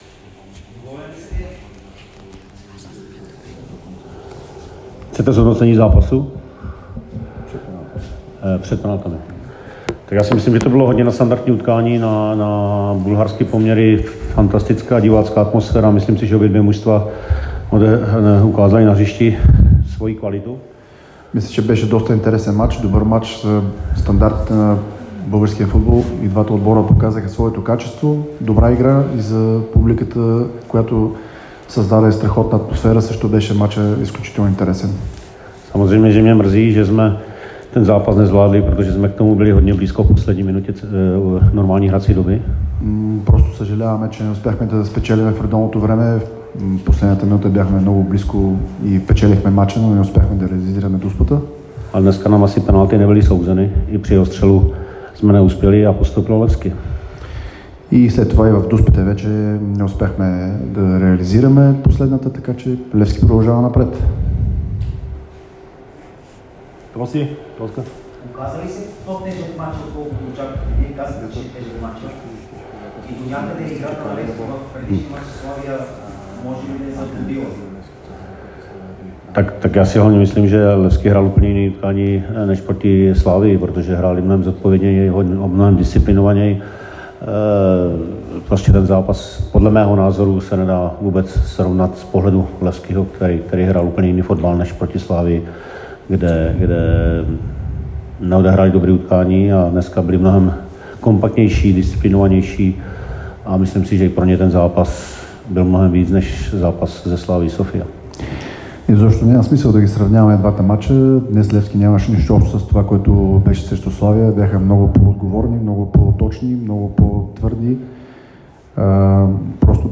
Чуйте цялото изказване на Павел Върба в аудиото Новините на Dsport и във Facebook , Viber , YouTube , TikTok и Instagram !